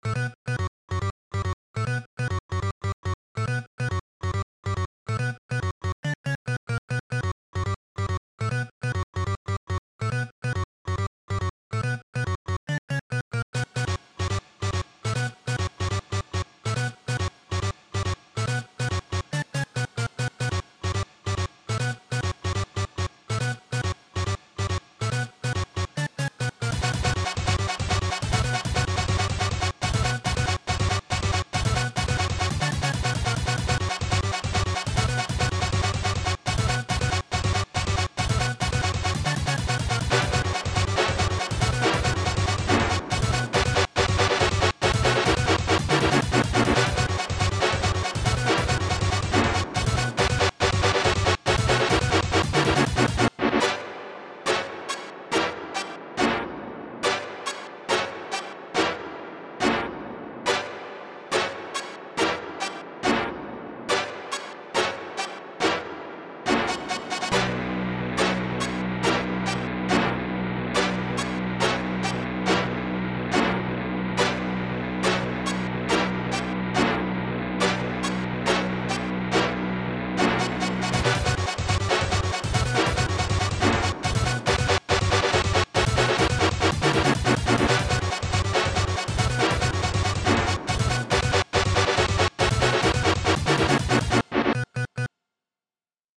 i tried making yet another horror piece!